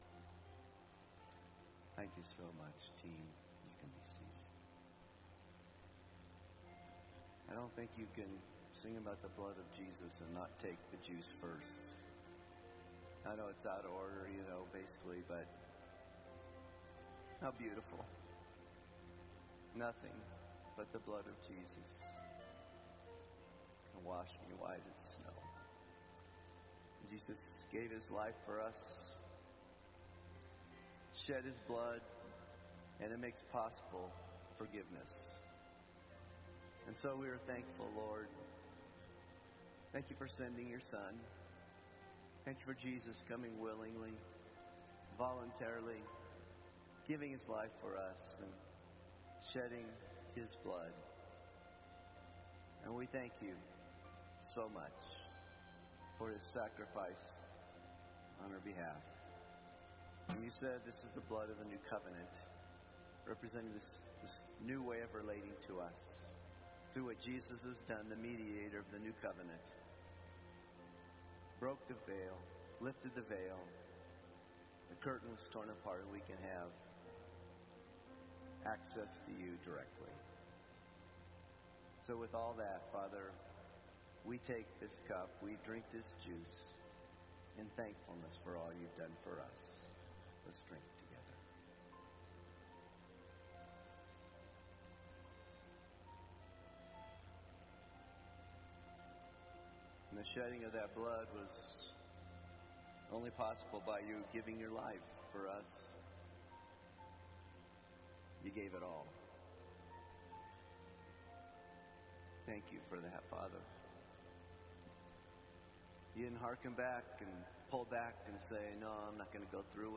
Majestic Baptist Church Sermon Series - Jacob